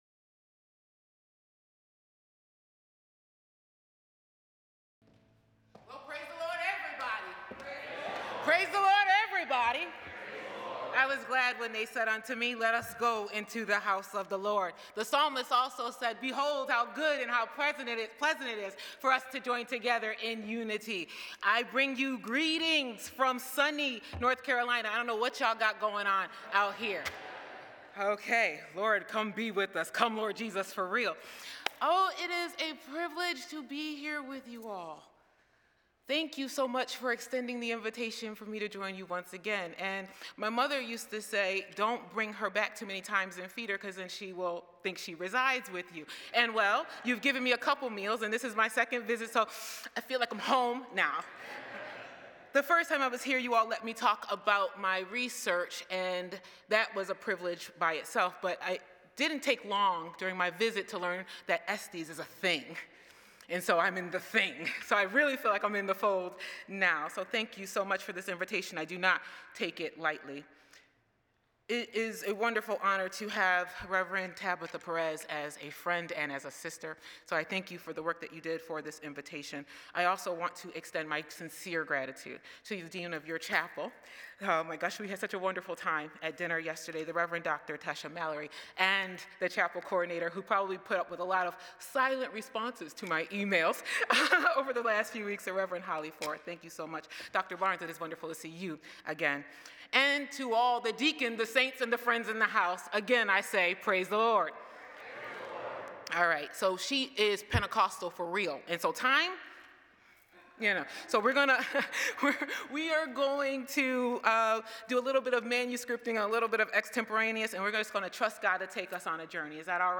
The following service took place on Thursday, February 19, 2026.